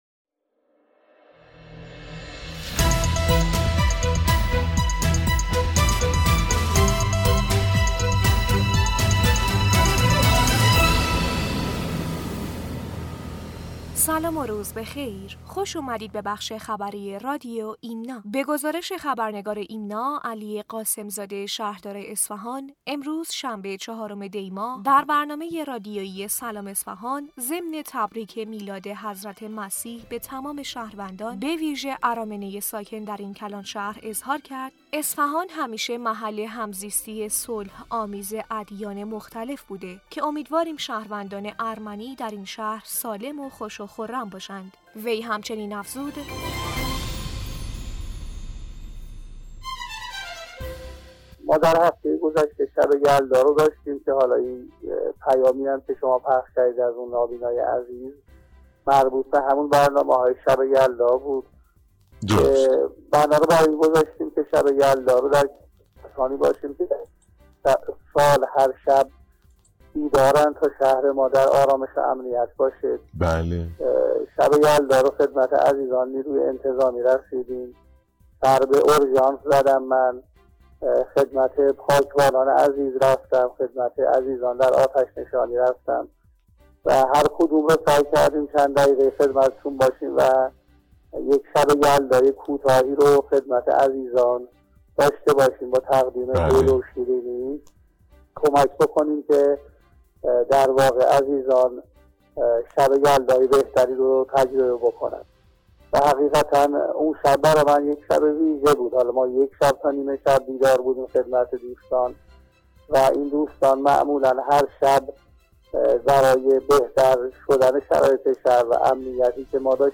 شهردار اصفهان در برنامه رادیویی «سلام اصفهان»، ضمن تبریک میلاد حضرت مسیح به تمام شهروندان به ویژه ارامنه ساکن در این کلانشهر اظهار کرد: اصفهان همیشه محل همزیستی صلح‌آمیز ادیان مختلف بوده که امیدواریم شهروندان ارمنی در این شهر سالم خوش و خرم باشند.
بسته خبری رادیو ایمنا/